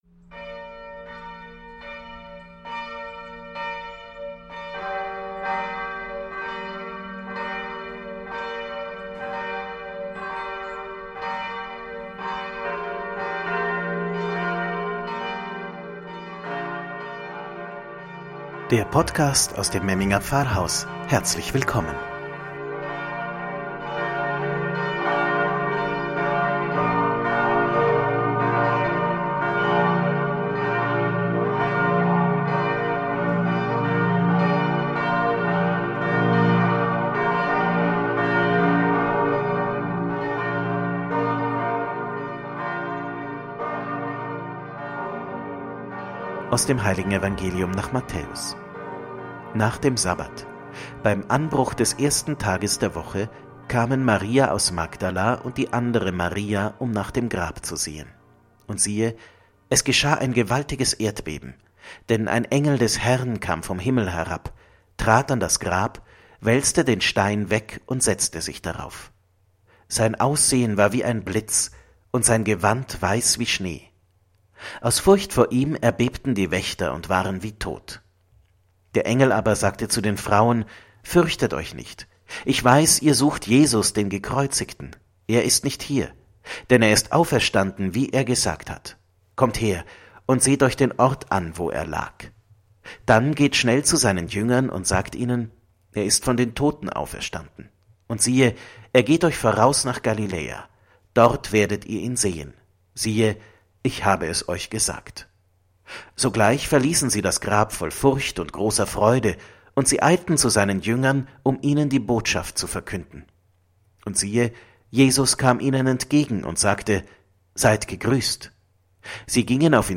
„Wort zum Sonntag“ aus dem Memminger Pfarrhaus – Halleluja!